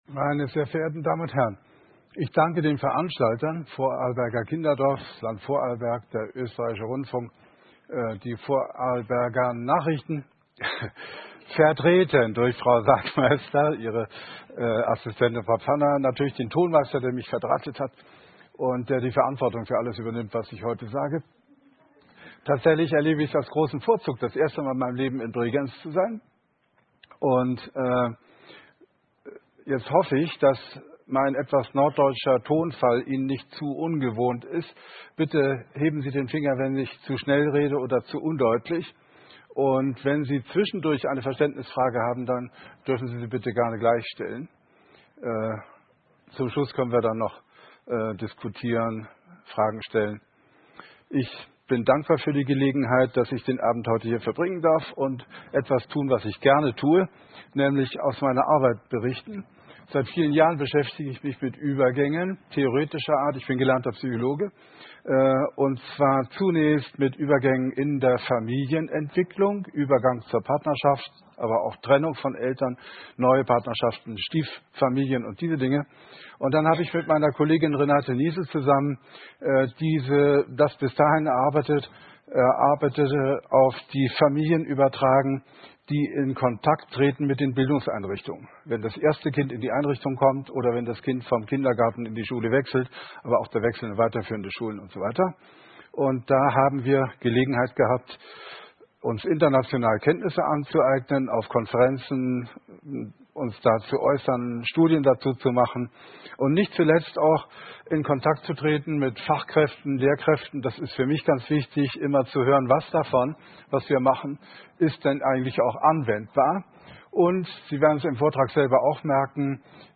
Vortrag